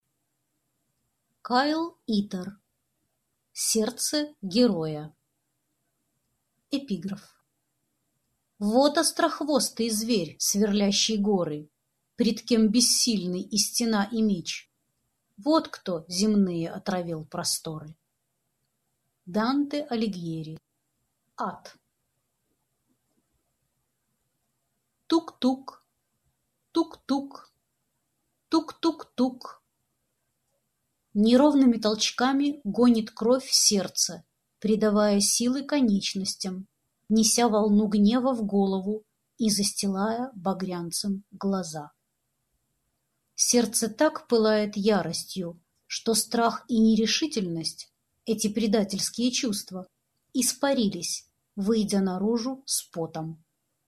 Аудиокнига Сердце героя | Библиотека аудиокниг
Прослушать и бесплатно скачать фрагмент аудиокниги